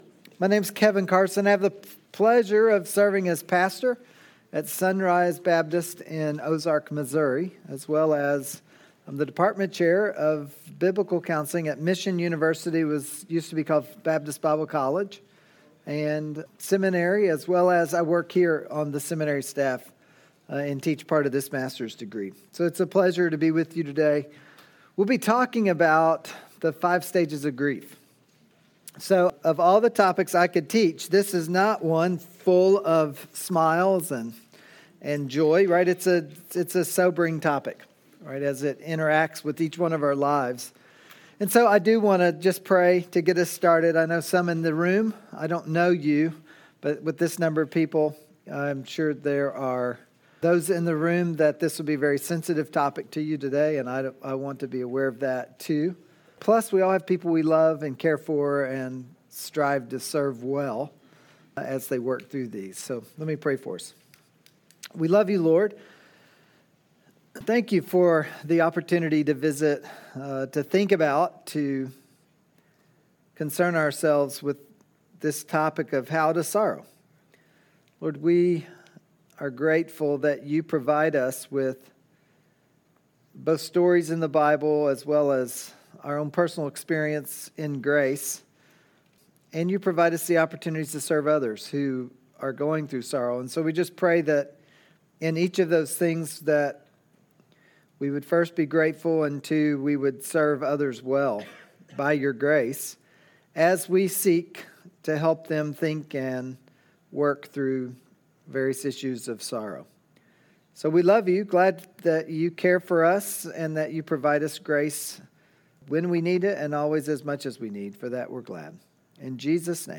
This is a session from a Biblical Counseling Training Conference hosted by Faith Church in Lafayette, Indiana.